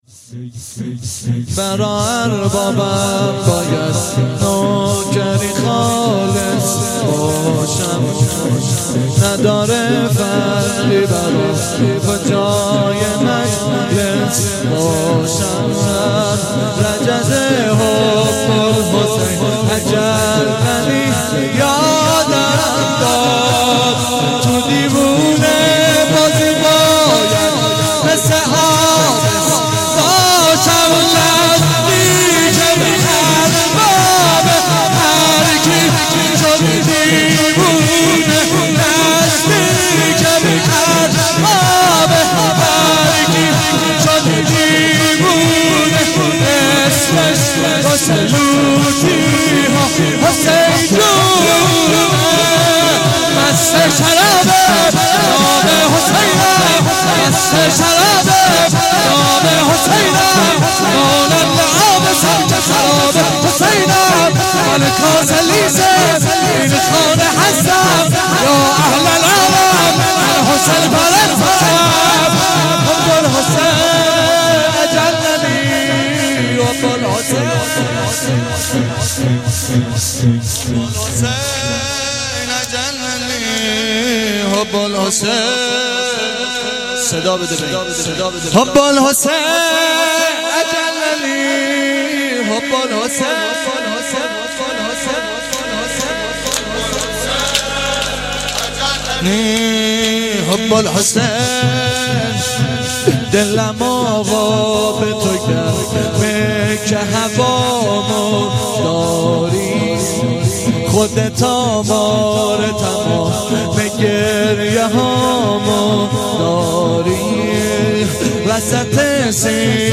شور - برا اربابم باید نوکری خالص باشم
روضه هفتگی